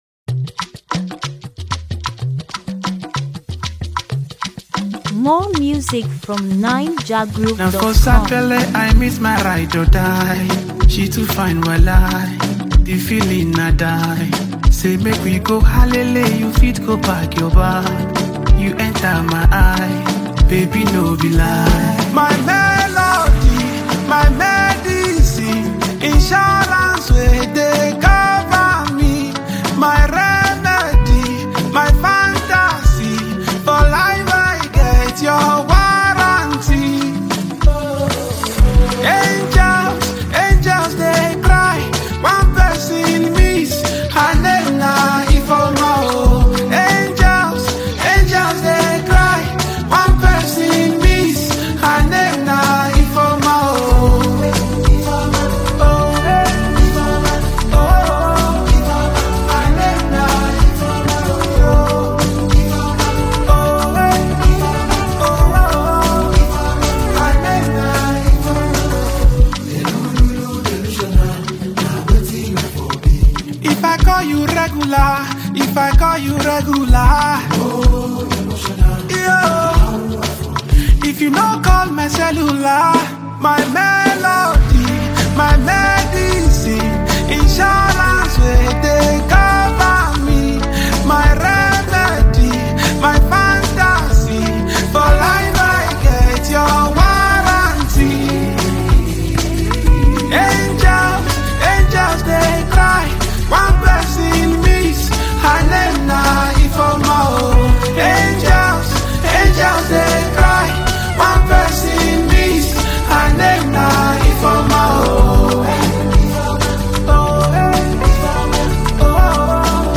Naija-music